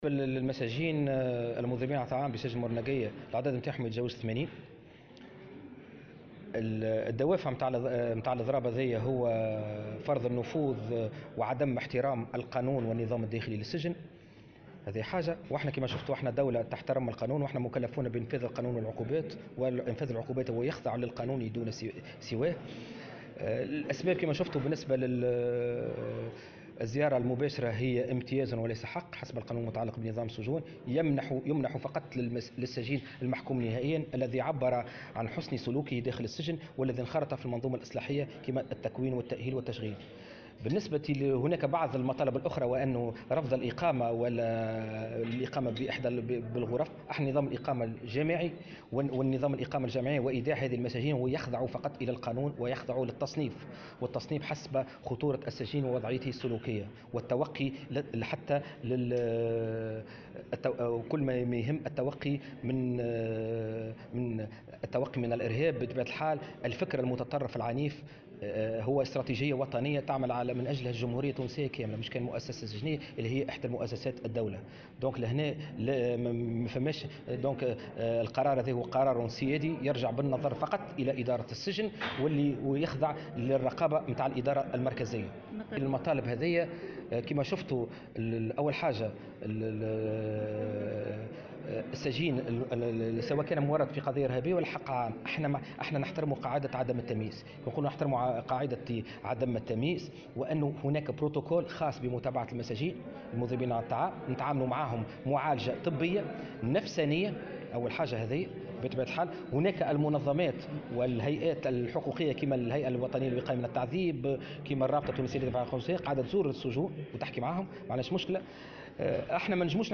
وأوضح في تصريح لمراسلة "الجوهرة أف أم" أن دوافع الإضراب هو " عدم احترام القانون والنظام الداخلي للسجن"، وفق تعبيره.